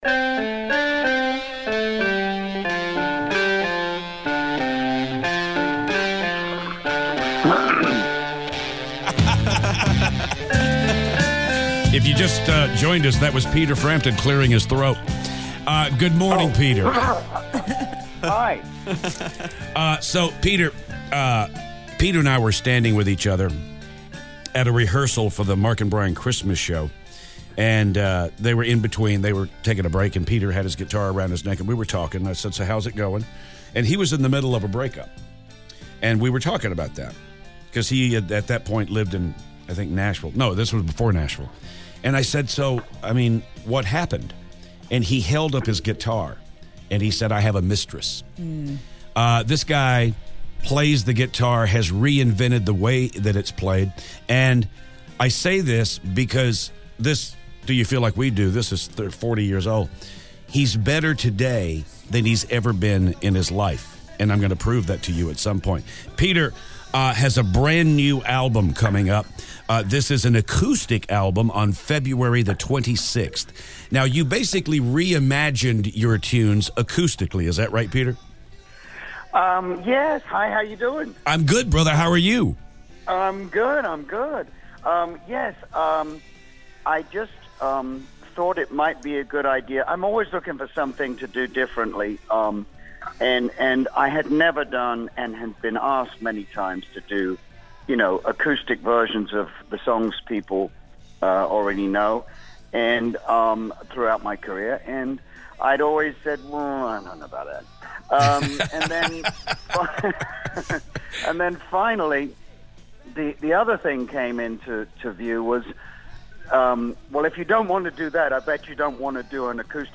Peter Frampton calls the show!